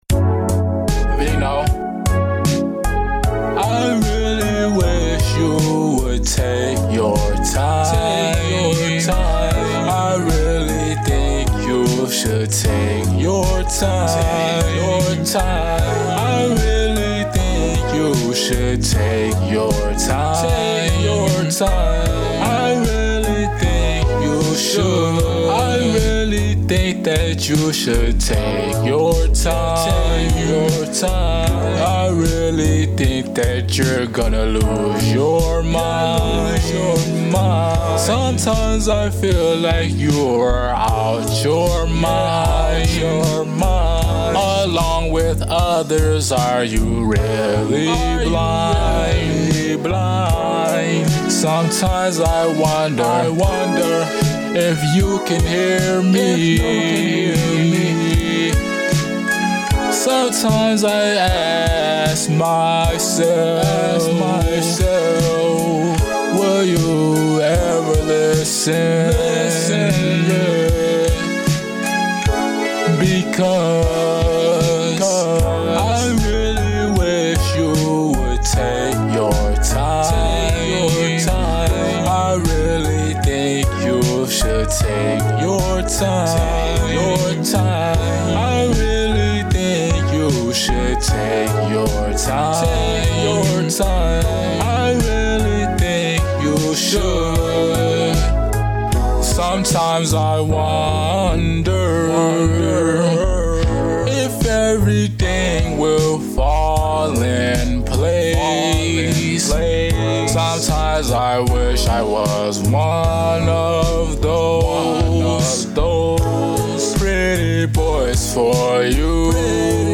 Bed Room Pop
Sad And Guitar Like Vibes